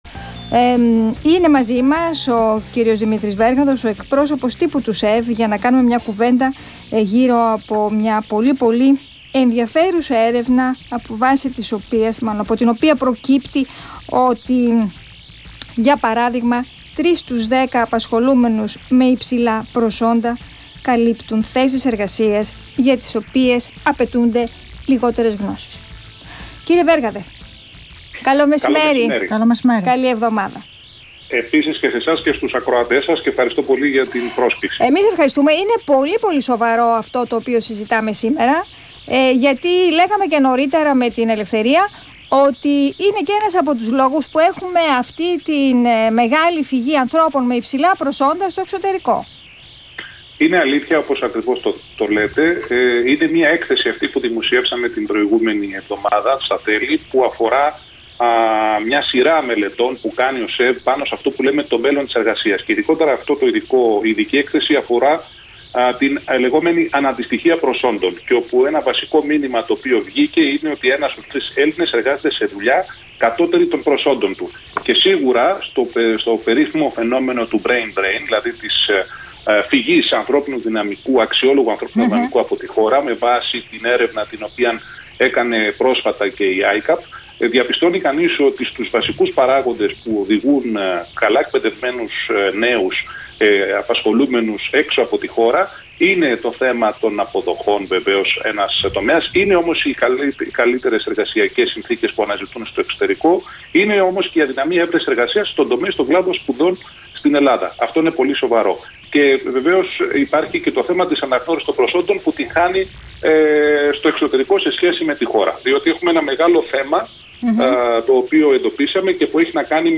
Ραδιοφωνική συνέντευξη